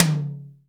RS TOM 1-3.wav